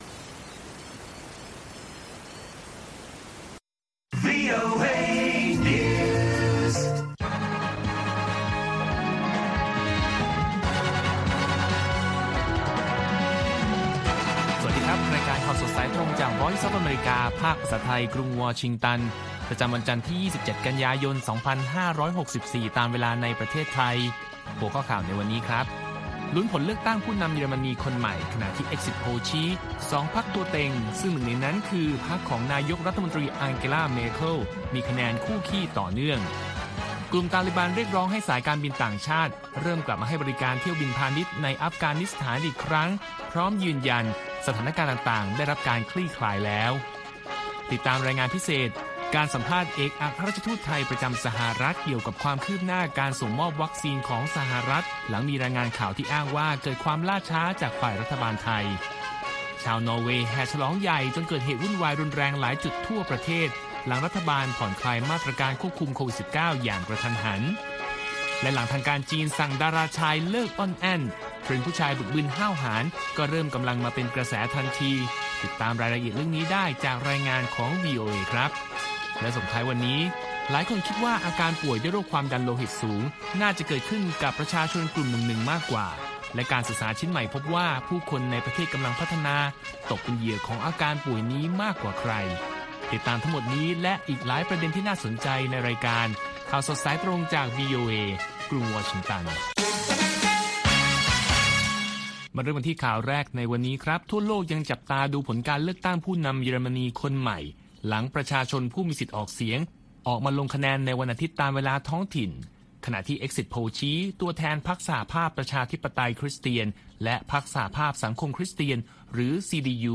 ข่าวสดสายตรงจากวีโอเอ ภาคภาษาไทย 8:30–9:00 น. ประจำวันที่ 27 กั่่นยายน 2564